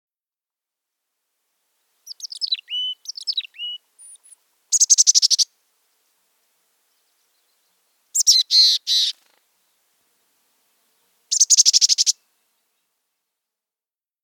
Mountain Chickadee
How they sound: Their call sounds like a lively but scolding chick-a-dee!